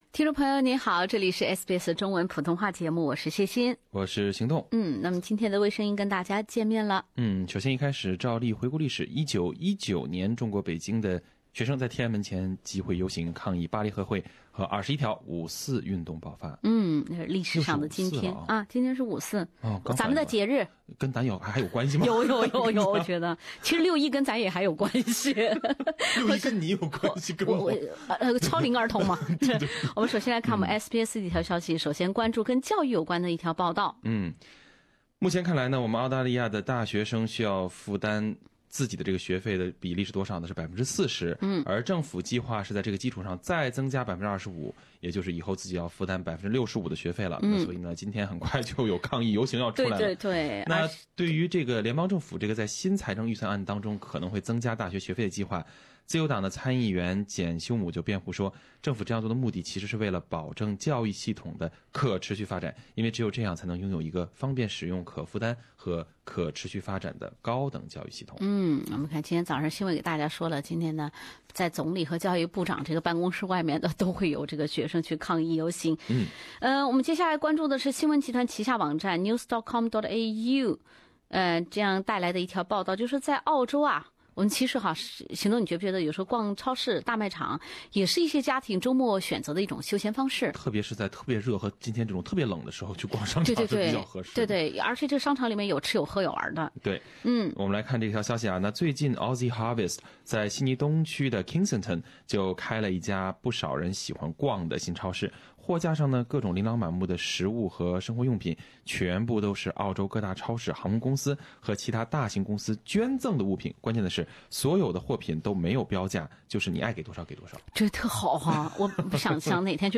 另类轻松的播报方式，深入浅出的辛辣点评，包罗万象的最新资讯，倾听全球微声音。